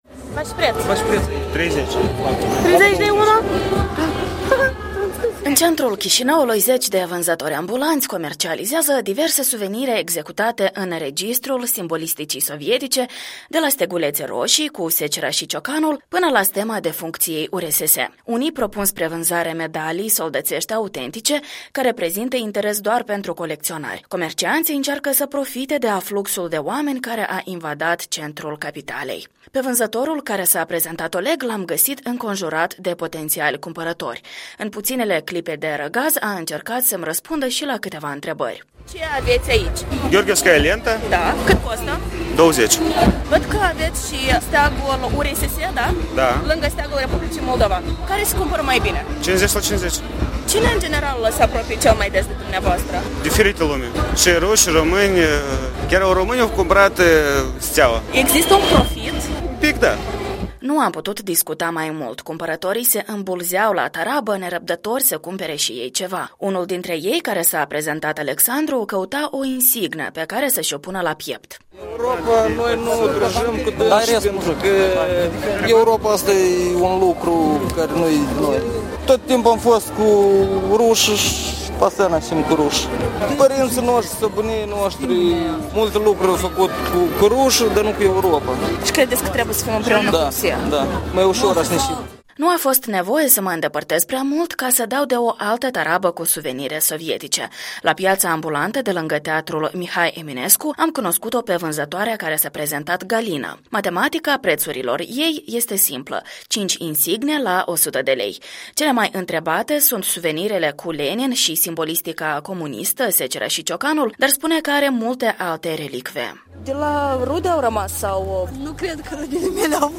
De ziua Victoriei, la piața de suveniruri din centrul Chișinăului.